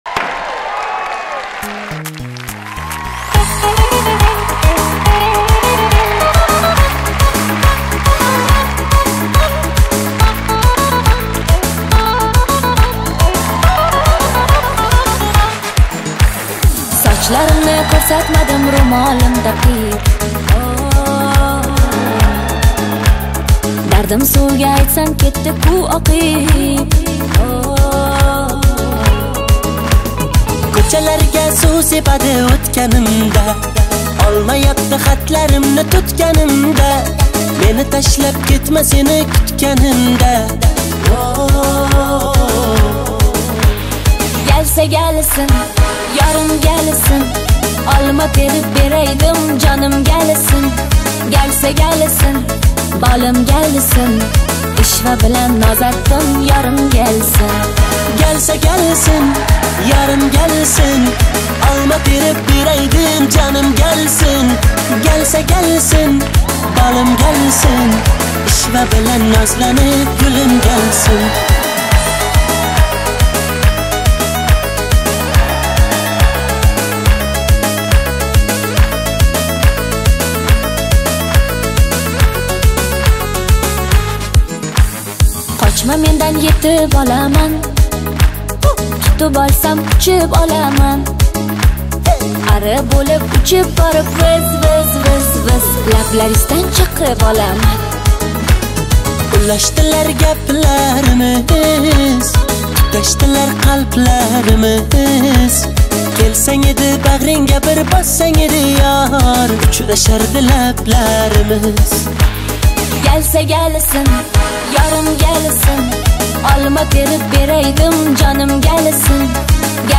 Узбекские песни